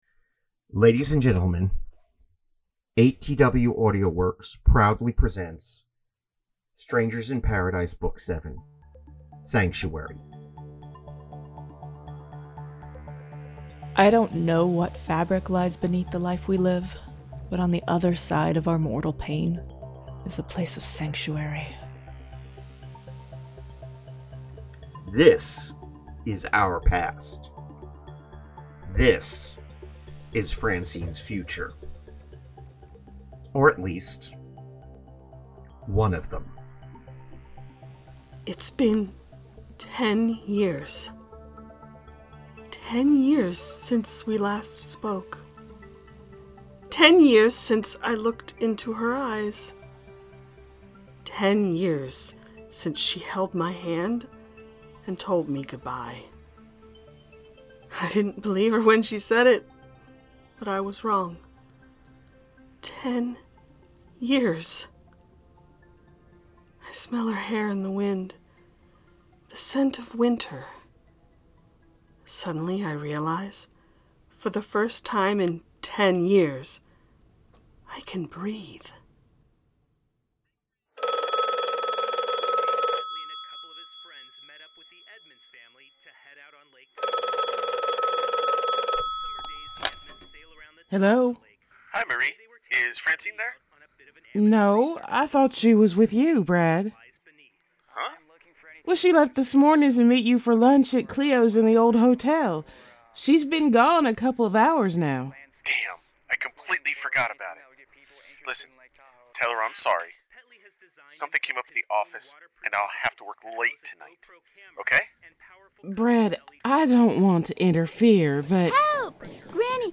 Strangers In Paradise – The Audio Drama – Book 7 – Episode 1 – Two True Freaks